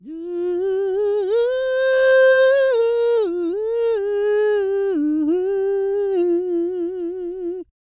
E-CROON 3023.wav